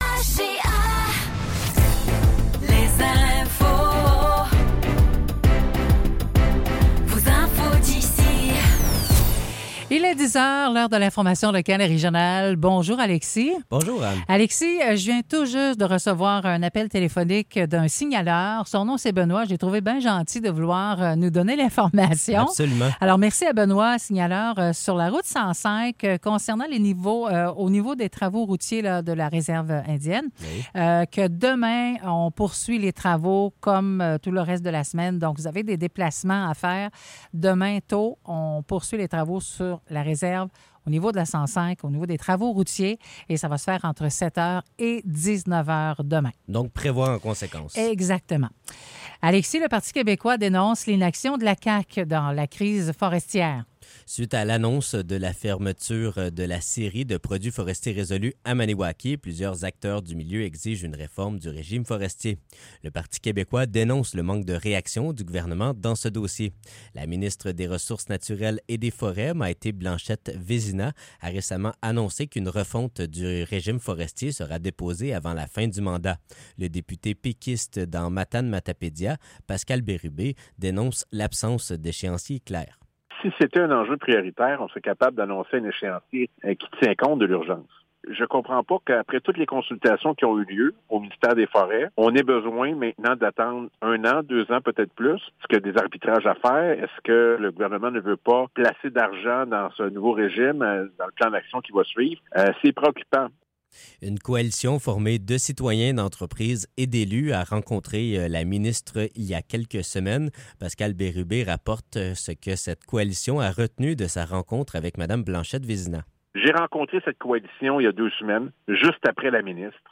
Nouvelles locales - 18 octobre 2024 - 10 h